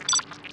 launchMenuOver.wav